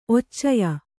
♪ occaya